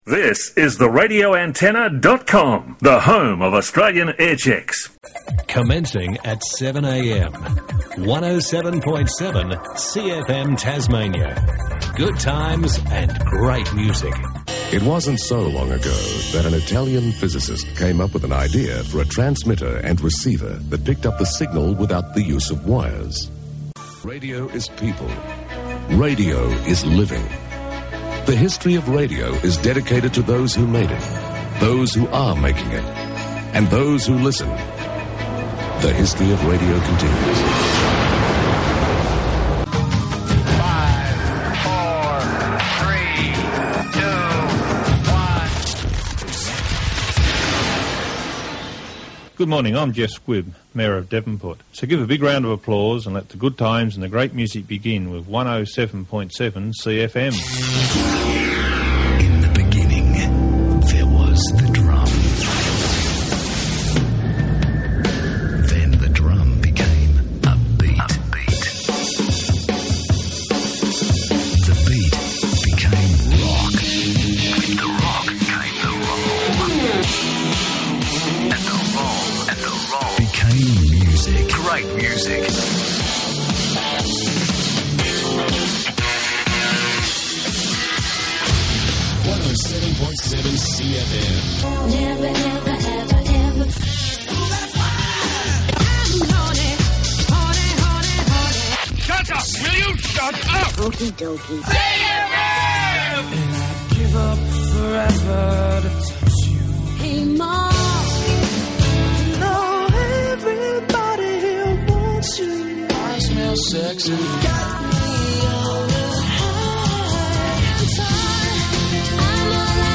Awesome aircheck… thanks for sharing!